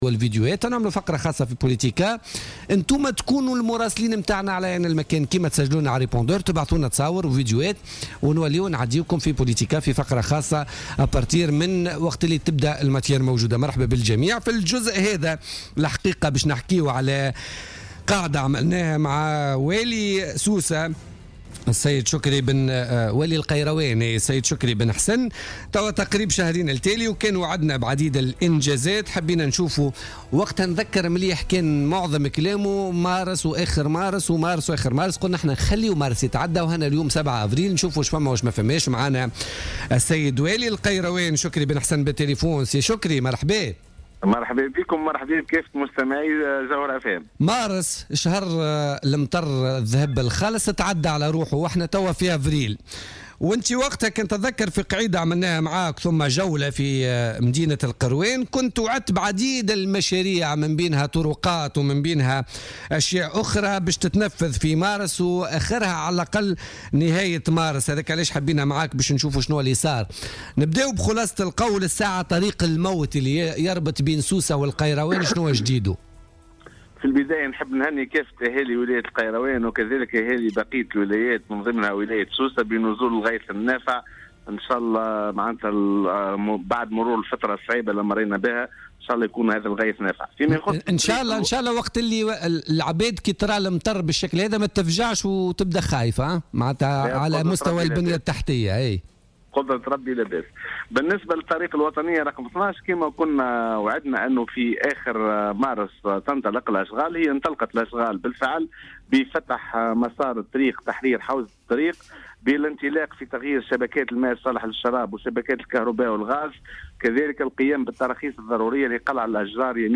أكد والي القيروان شكري بن حسن في مداخلة له في بوليتيكا اليوم الخميس 7 أفريل 2016 أن اشغال الطريق الوطنية رقم 12 الرابطة بين سوسة والقيروان انطلقت في اخر مارس بفتح مسار الطريق وتوسعته وتغيير شبكات الكهرباء والغاز والماء الصالح للشراب والقيام بالتراخيص الضرورية لقلع الأشجار في محيط الطريق.